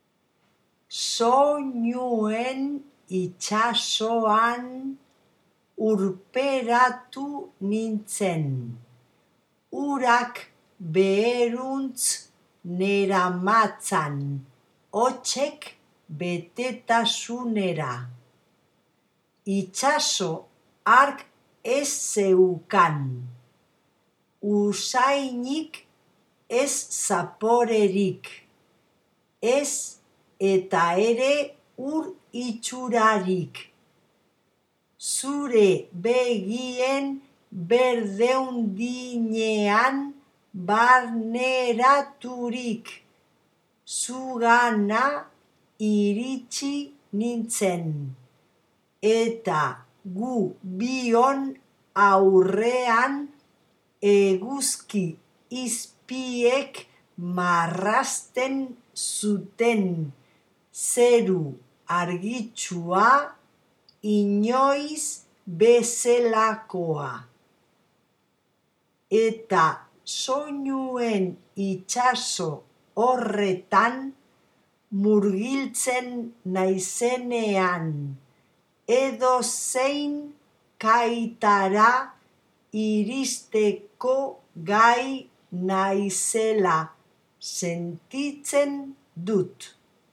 Soinuen-itsasoa-TEXTO-leido.mp3